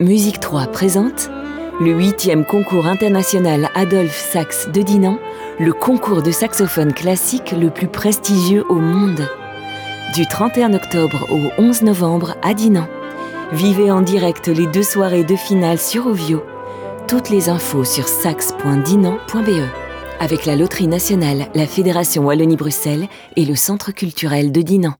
Spot radio pour le Concours
M3-Promo_Sax_Dinant_23.wav